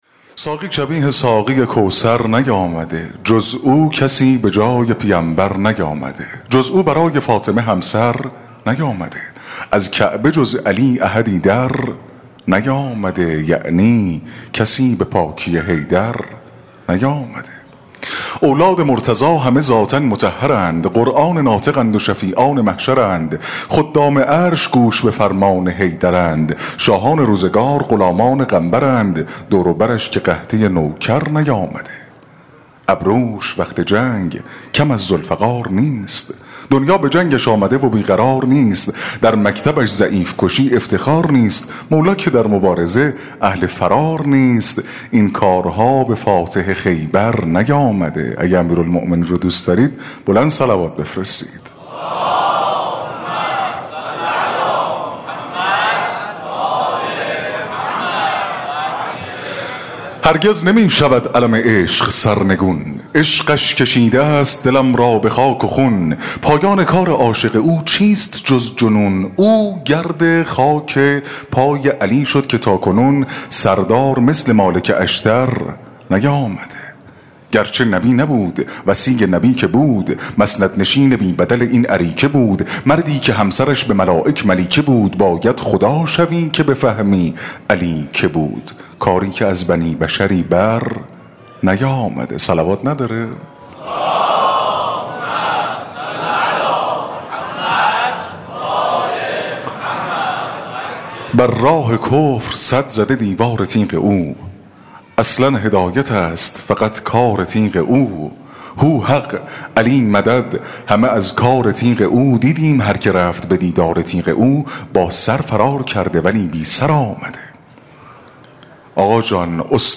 مراسم شب سوم دهه اول محرم
شعر خوانی